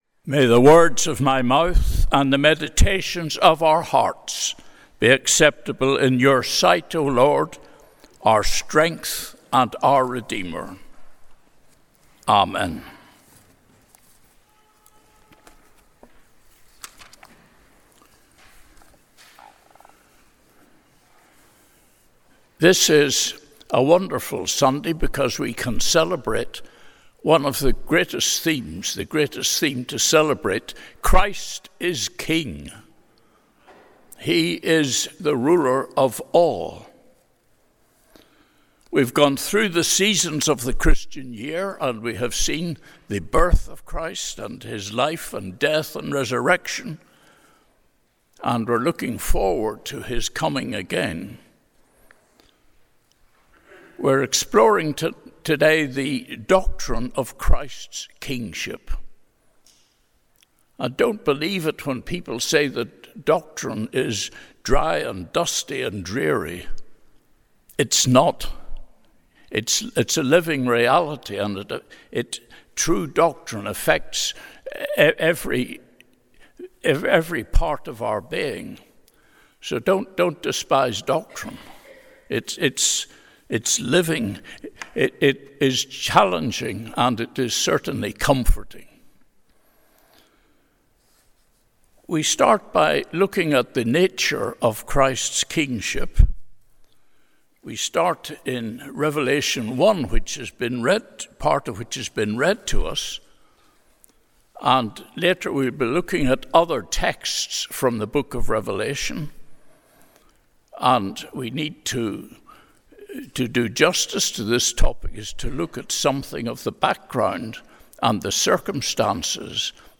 Sunday Worship–November 24, 2024